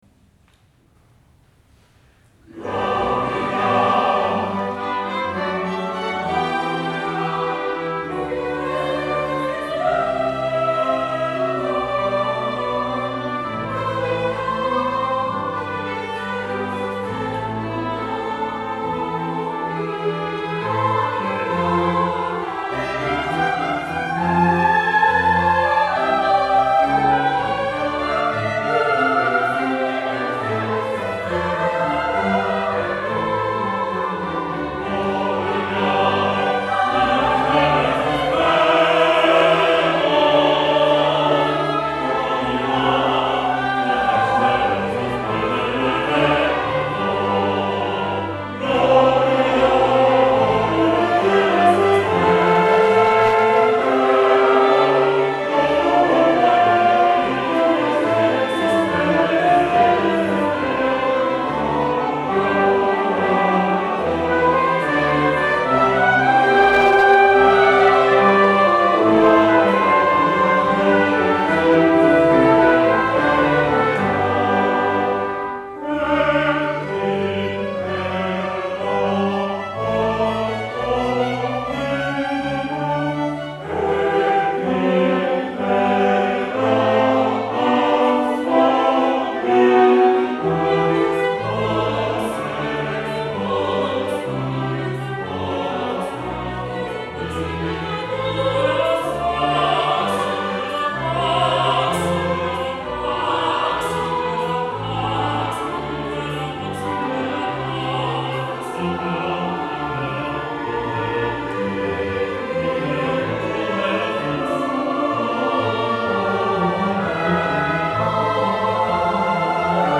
First performance Bach Chor, ToKyo - ARS on 15 Jun 2024 at Misakicho Church
Midi Instrumental ensemble (Ob-Ob-Hr-Trb-Org)